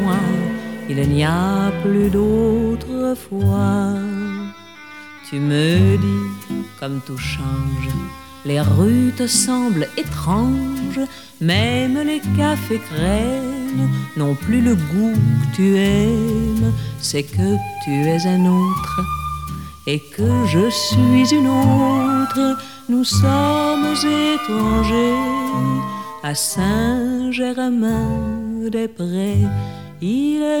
• Chanteur :